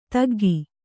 ♪ taggi